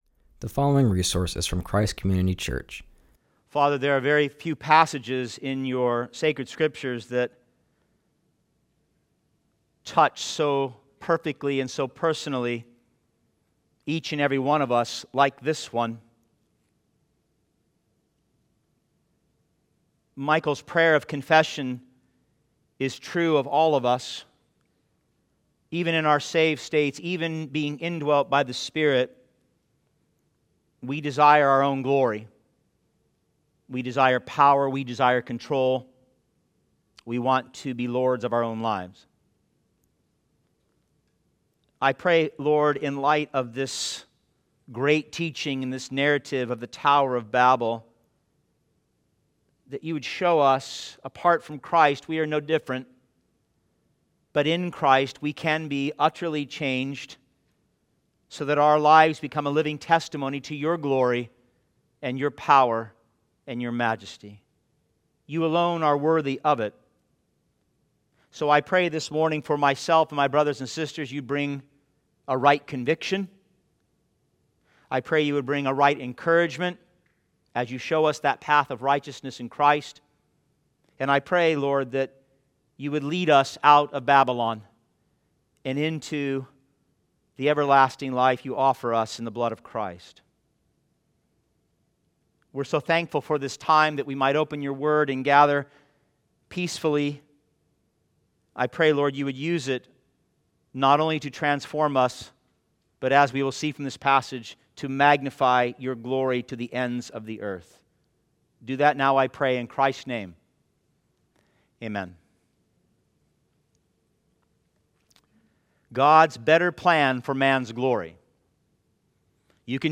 continues our series and preaches from Genesis 11:1-9.